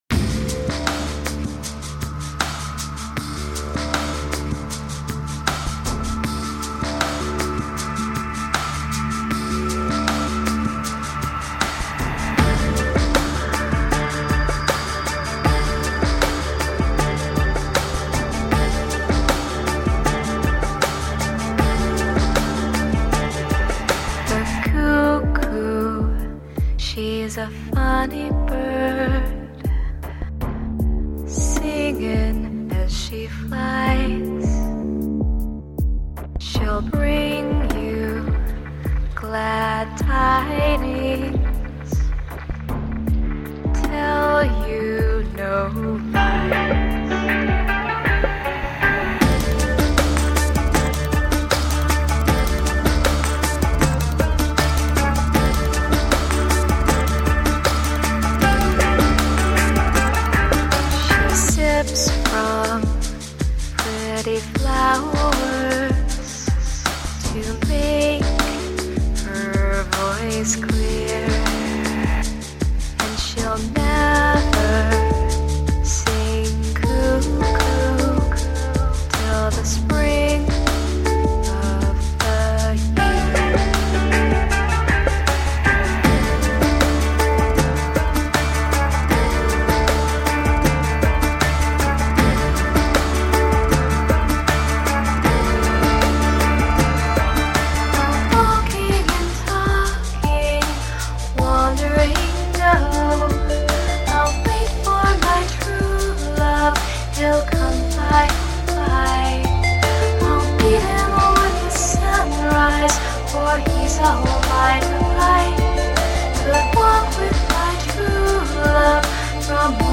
subdued and seductive